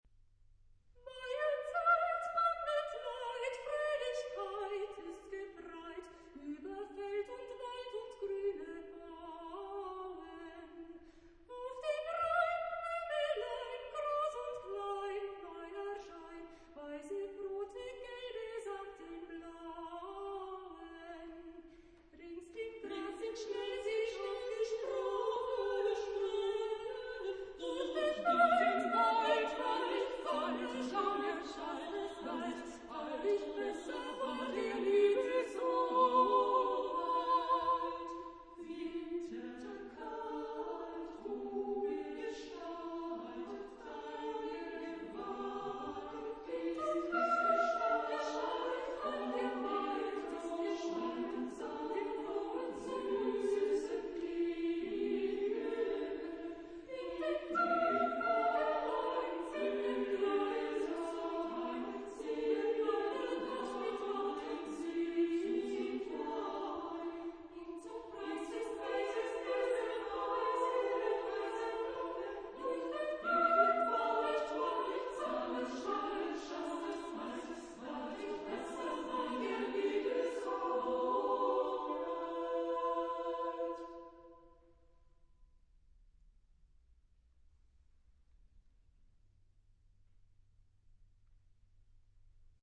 Genre-Style-Forme : Profane ; Populaire
Type de choeur : SSA  (3 voix égales de femmes )
Tonalité : mi mineur